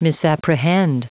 Prononciation du mot misapprehend en anglais (fichier audio)
Prononciation du mot : misapprehend